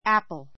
apple 小 A1 ǽpl ア プる 名詞 リンゴ ; apple tree とも リンゴの木 eat an apple eat an apple リンゴを食べる pare [peel] an apple pare [peel] an apple リンゴの皮をむく 参考 リンゴはアメリカ人の大好物で最もアメリカ的なものと考えられている.